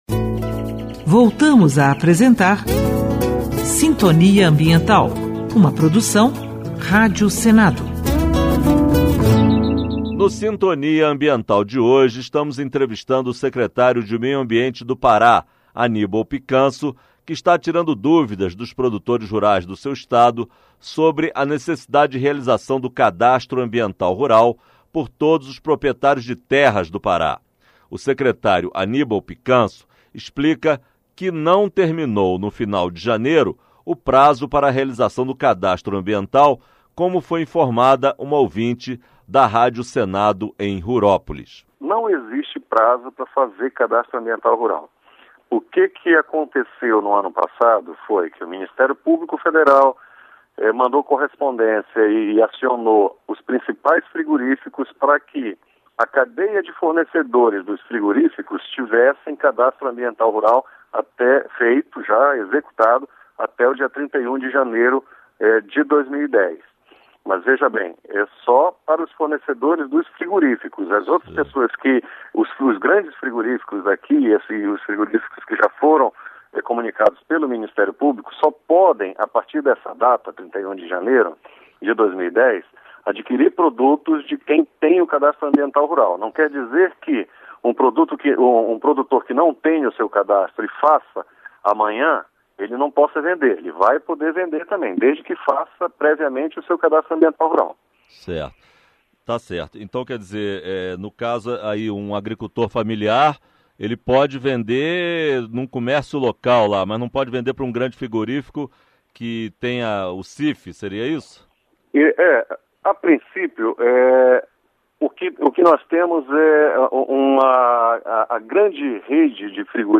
Entrevista com o secretário de meio ambiente do Pará, Anibal Picanço.